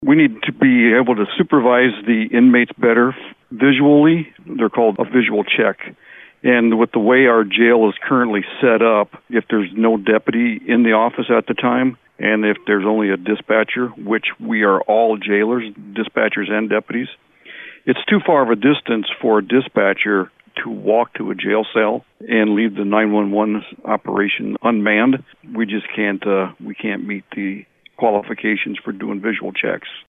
He tells us what they believe needs to be done.